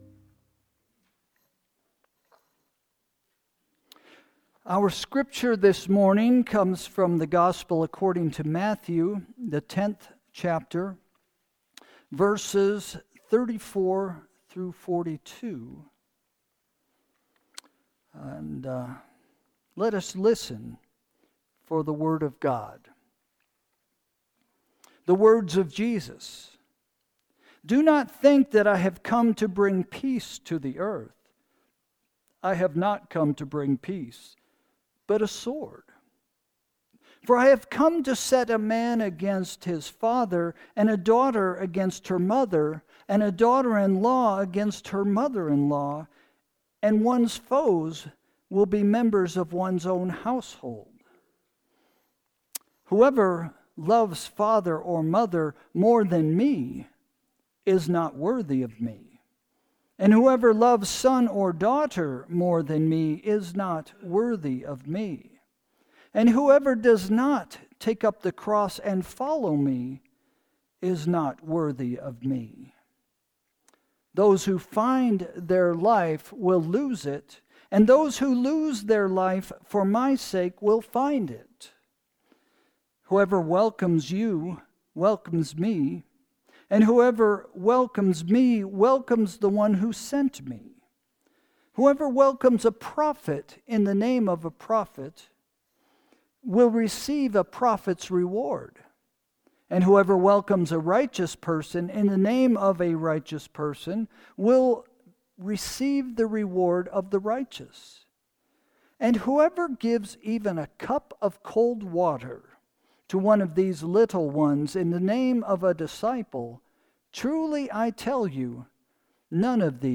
Sermon – March 9, 2025 – “Find Life” – First Christian Church